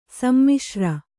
♪ sammiśra